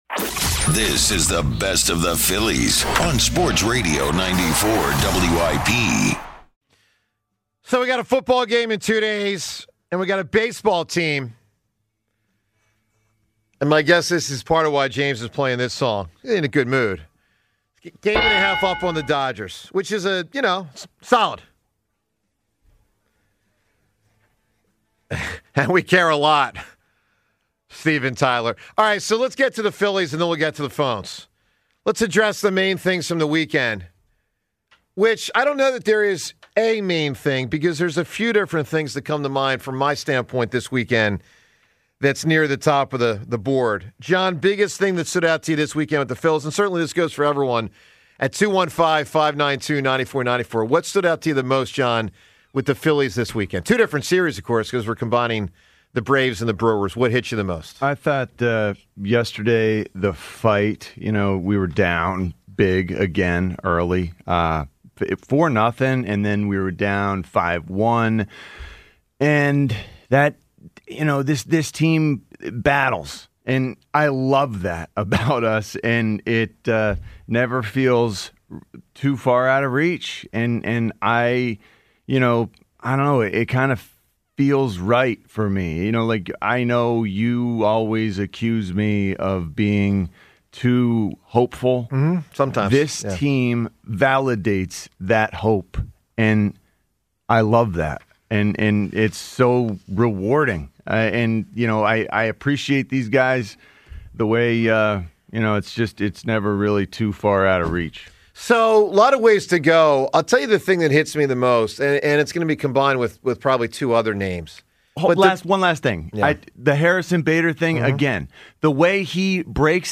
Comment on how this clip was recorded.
You can catch the 94WIP Morning Show live on SportsRadio 94WIP weekdays from 6–10 a.m. ET.